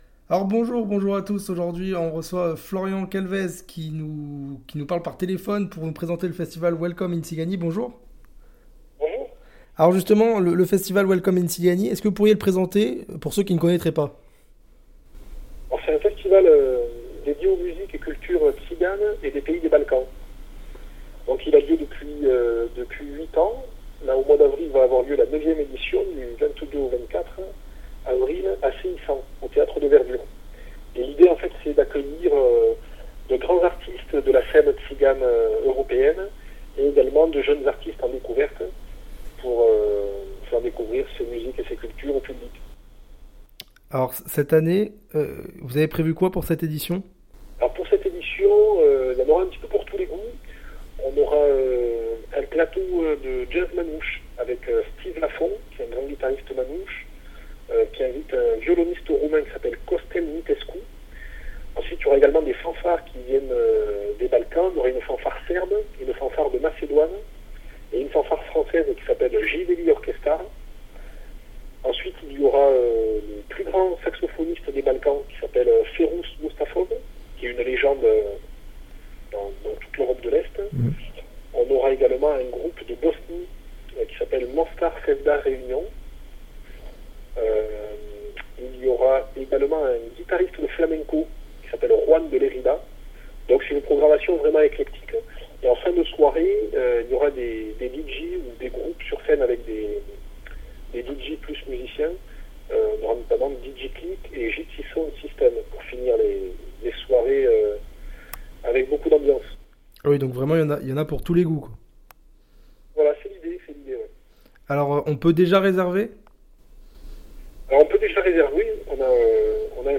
Ci-dessous l’interwiew